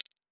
GenericButton10.wav